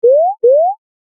alarm.mp3